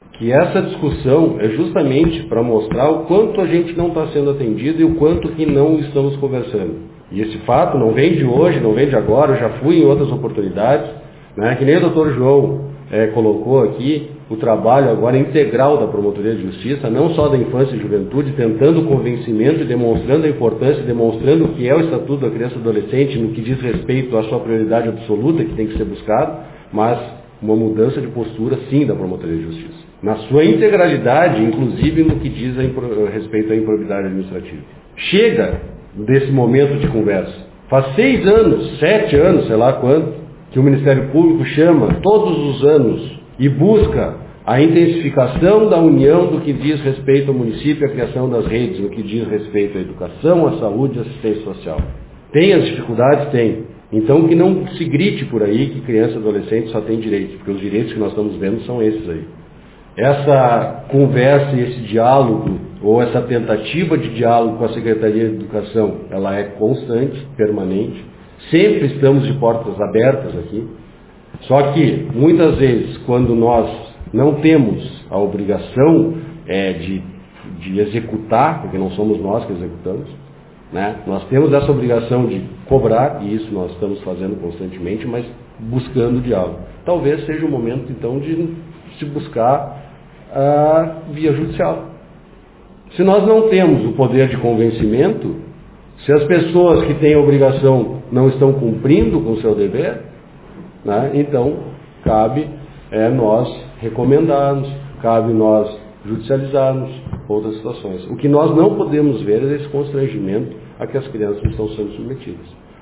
Em coletiva de imprensa realizada na manhã de hoje, os promotores João Pedro Togni e Rogério Fava Santos, apresentaram situações precárias de diferentes educandários da cidade.
O promotor Rogério destacou que este problema não é atual e que já se estende há anos: